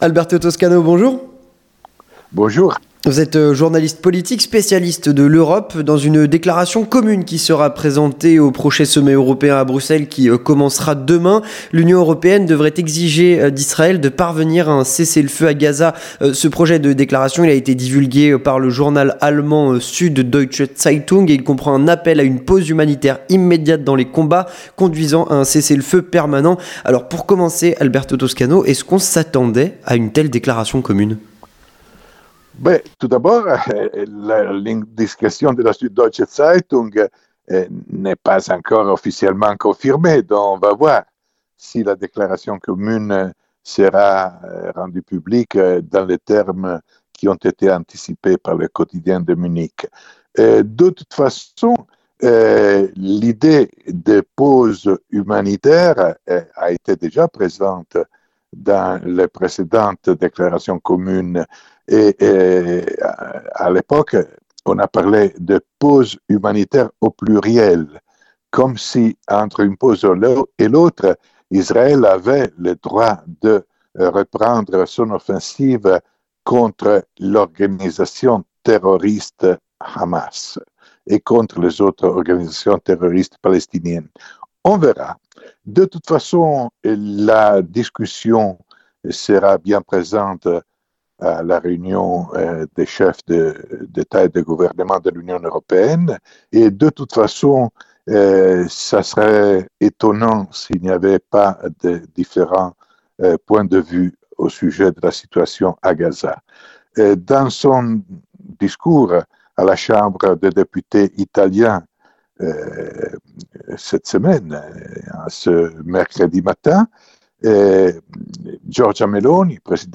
L'entretien du 18H - Dans une déclaration commune qui sera présentée au sommet européen, l’UE devrait exiger d’Israël de parvenir à un cessez-le-feu à Gaza.
journaliste politique, spécialiste de l'Europe.